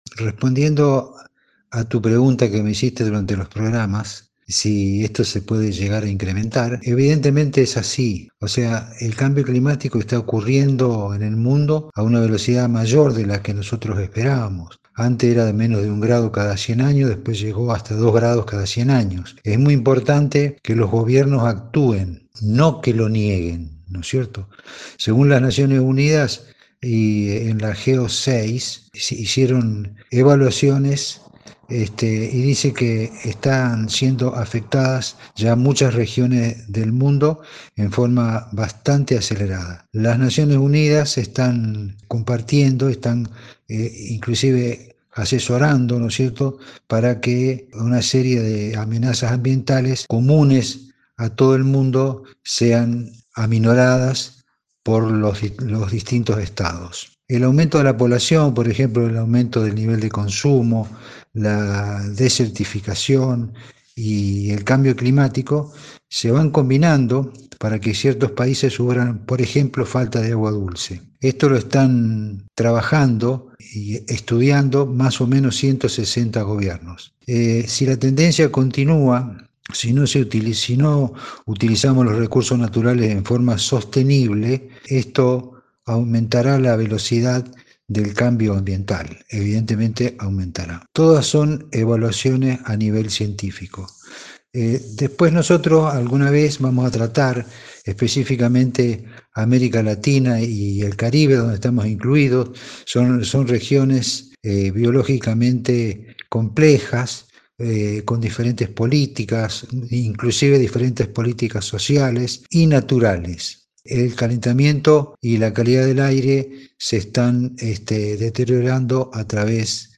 En una entrevista  con nuestra radio